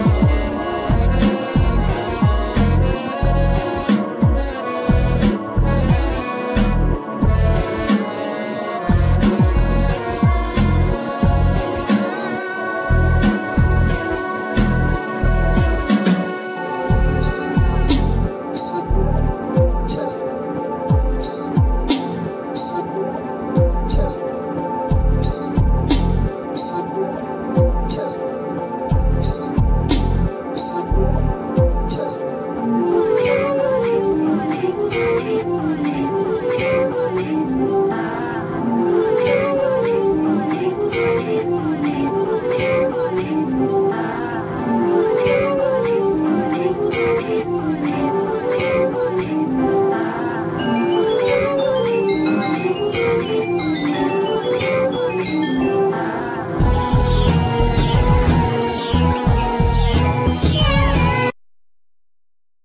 Vocal,Synthsizer,Harmonica
Drums,Keyboards
Guitar,Mandlin
Bass,Melodica
Cello,Bass